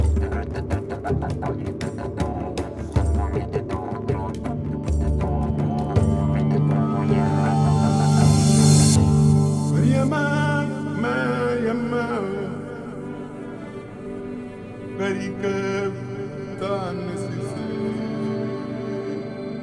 Musique multipistes.